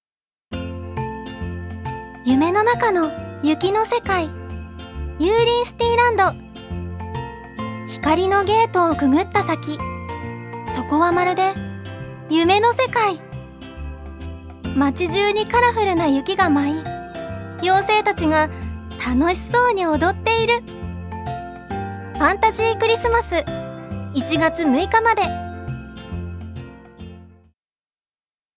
ジュニア：女性
ナレーション４